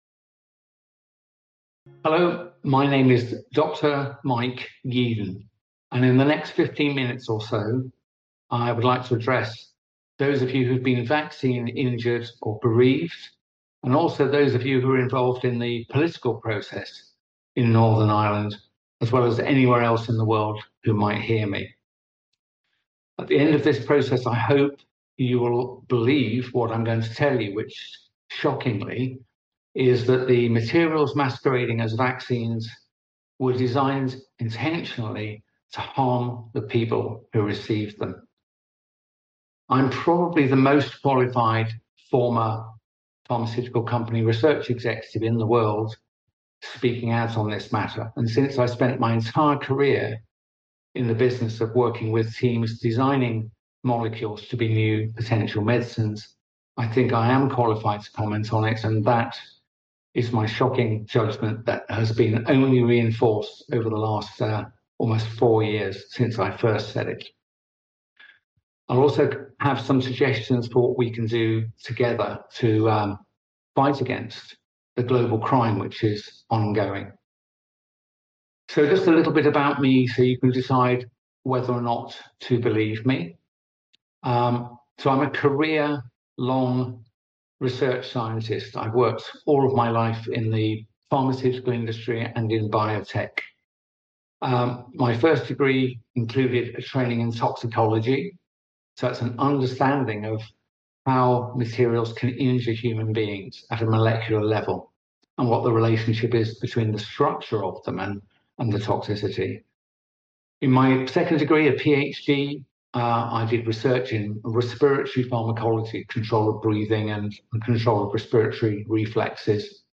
Mike Yeadon presentation to Northern Irish Parliament